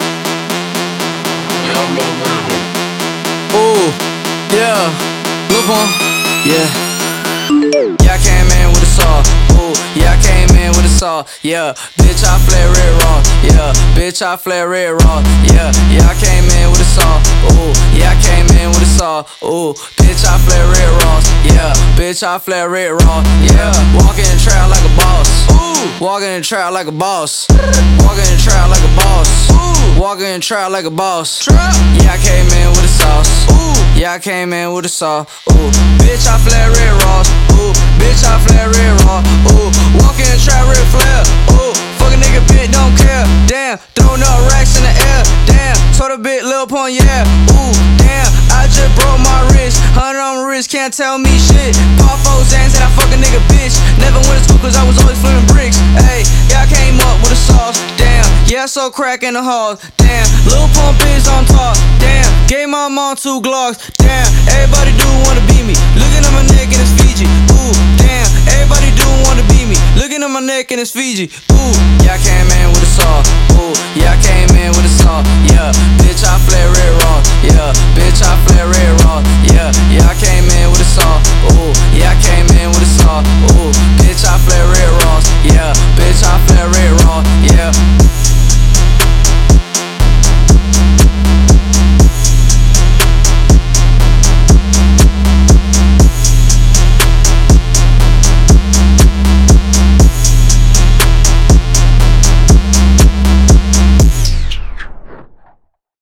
Зарубежный рэп